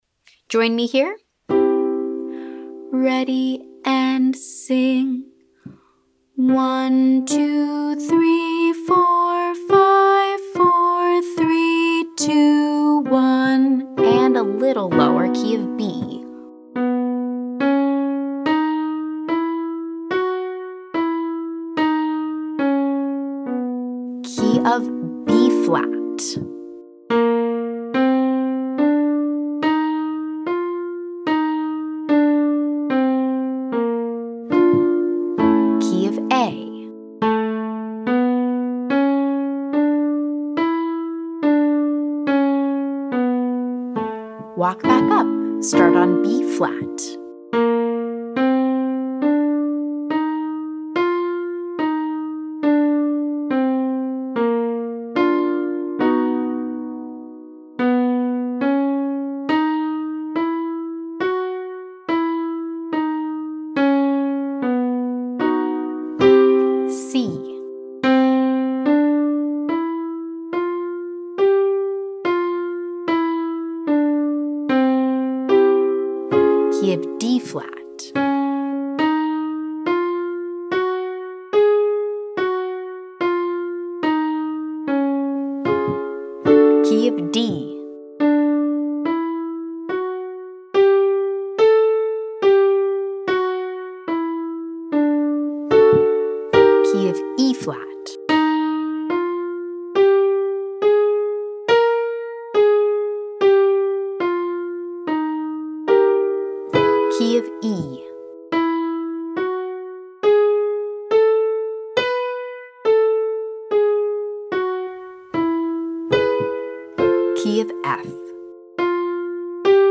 We’ll walk up and down, like this.
Exercise - 5-tone scale